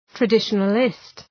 {trə’dıʃənəlıst}